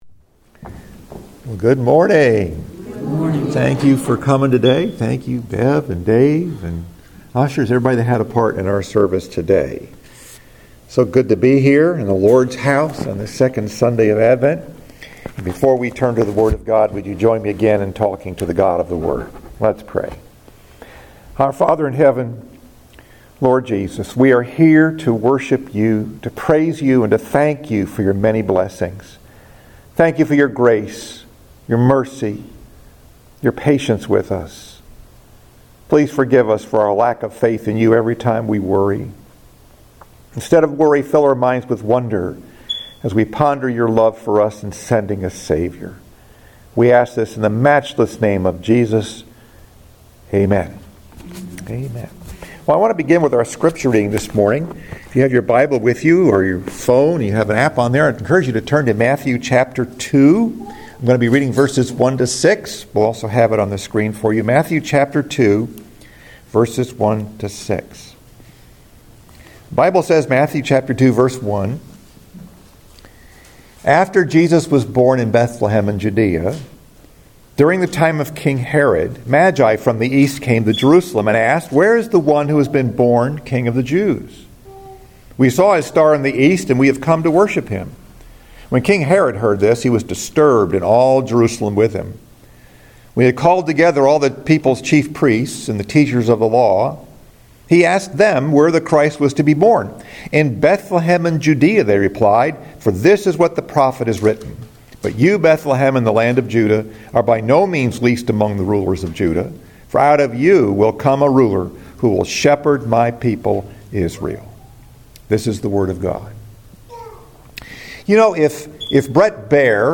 Message: “When God Came to Town” Scripture: Matthew 1 & 2